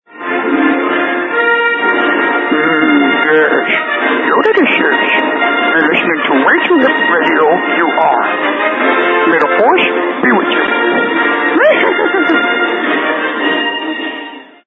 LINER - Yoda
Category: Radio   Right: Personal